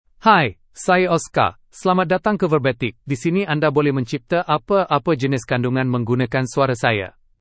MaleMalayalam (India)
OscarMale Malayalam AI voice
Voice sample
Listen to Oscar's male Malayalam voice.
Male